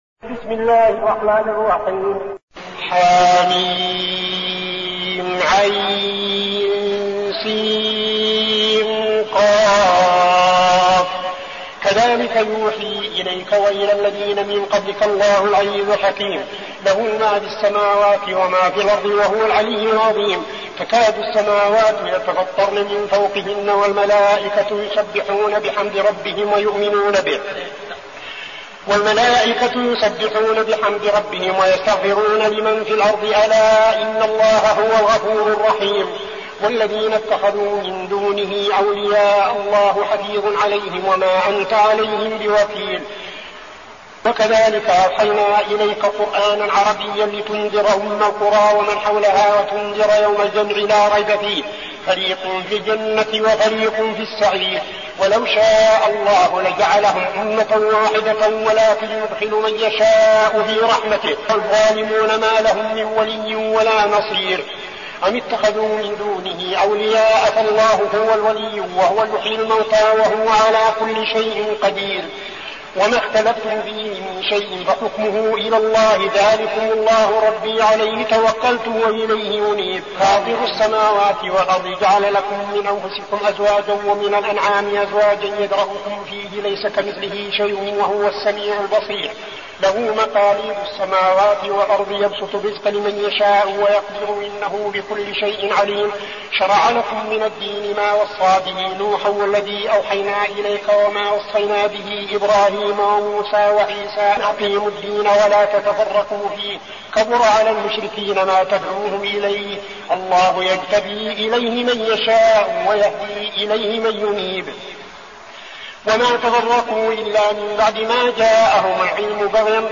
المكان: المسجد النبوي الشيخ: فضيلة الشيخ عبدالعزيز بن صالح فضيلة الشيخ عبدالعزيز بن صالح الشورى